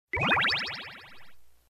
audio_exit_room.mp3